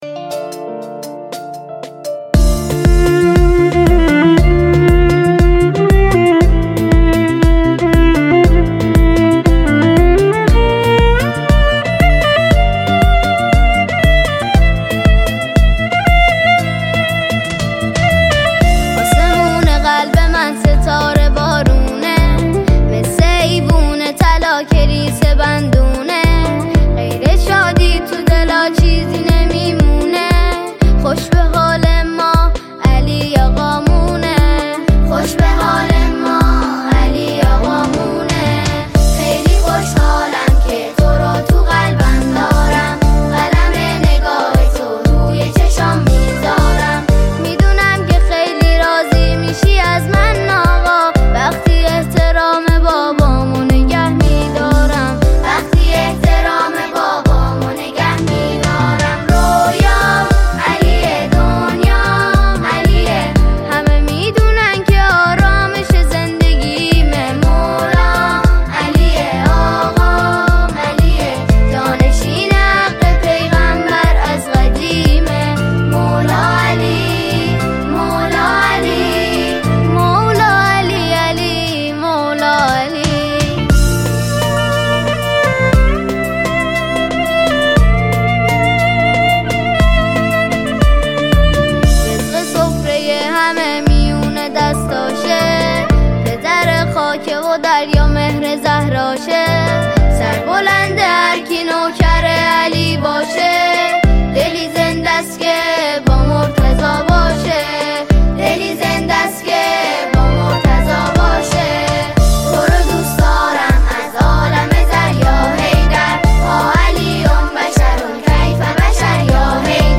به مناسبت فرارسیدن عید غدیر خم